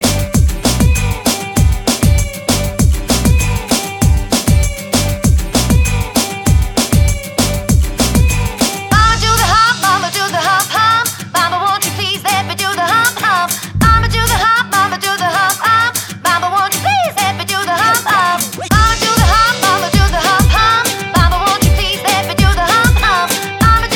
For Solo Rapper Pop